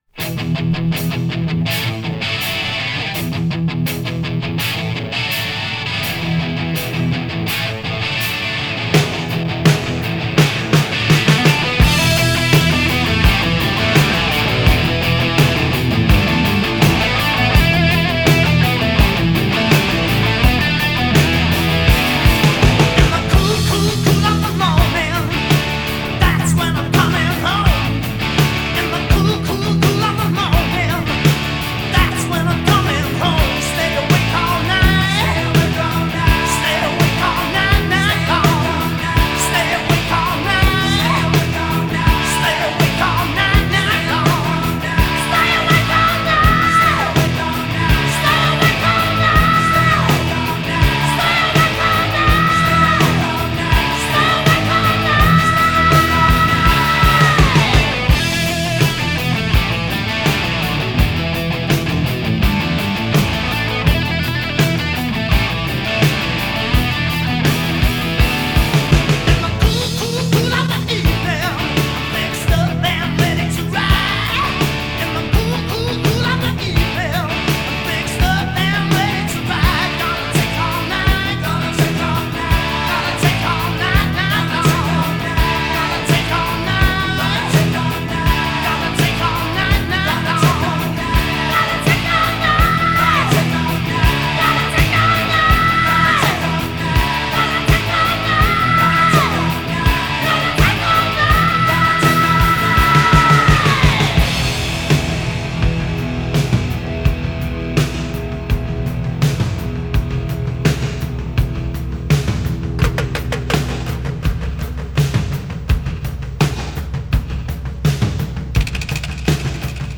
Жанры: Хэви-метал, Хард-рок